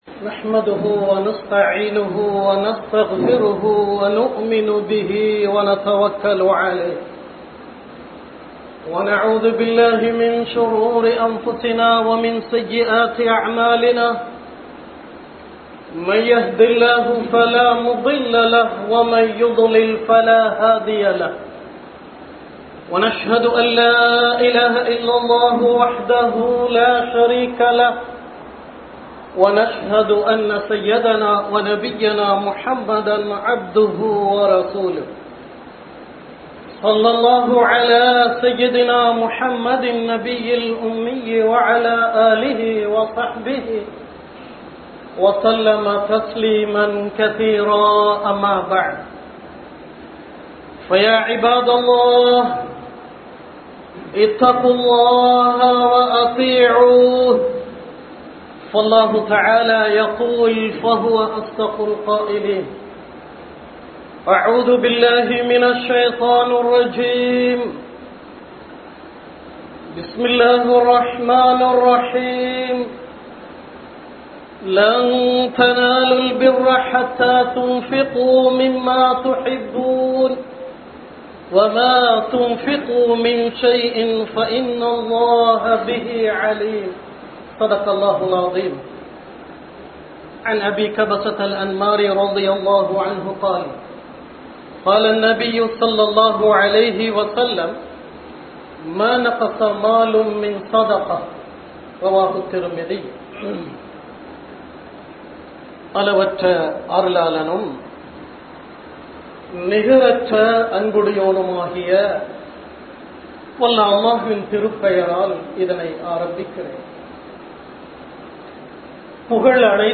மரணிப்பதற்கு முன் அமல் செய்வோம் | Audio Bayans | All Ceylon Muslim Youth Community | Addalaichenai
Jumua Masjidh